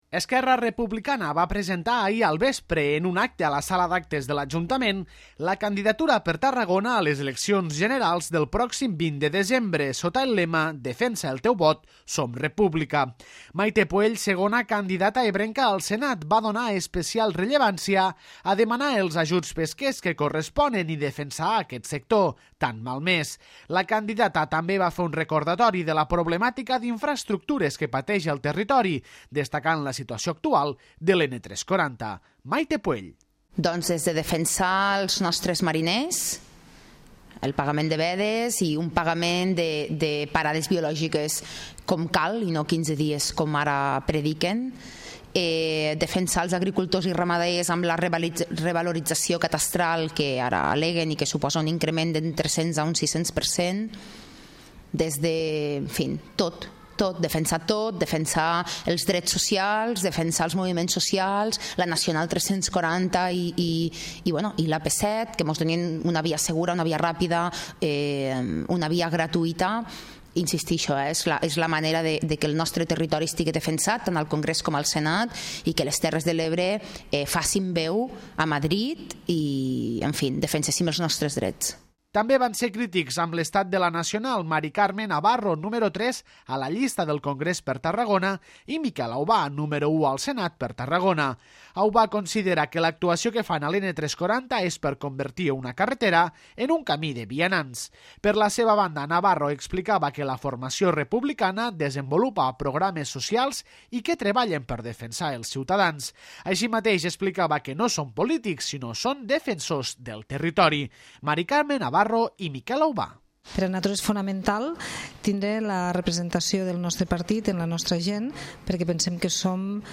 Esquerra Republicana va presentar ahir al vespre, en un acte a la Sala d'Actes de l'Ajuntament, la candidatura per Tarragona a les eleccions generals, del pròxim 20 de desembre, sota el lema ''Defensa el teu vot, Som República'